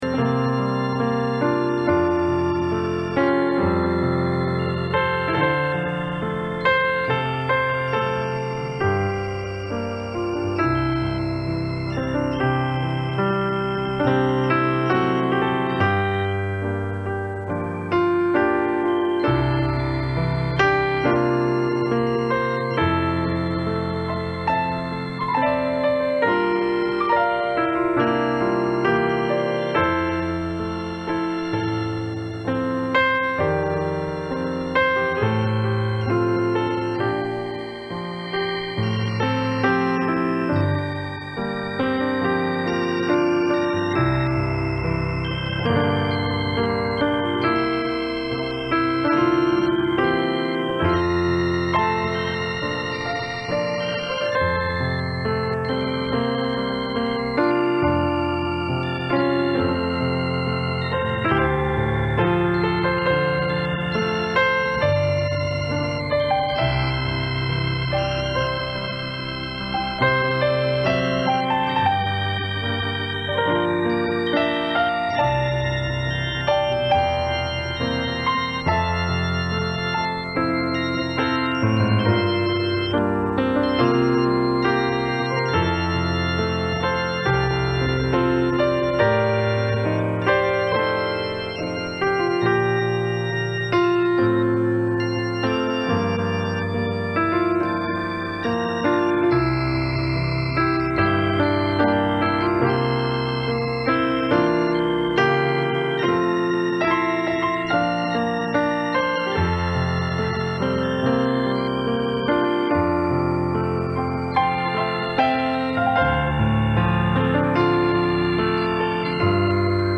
Series: Sunday Evening Services
Service Type: Sunday Evening